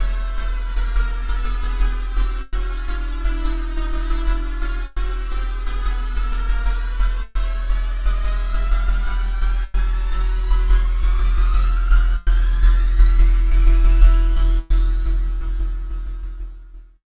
58-PHASE  -R.wav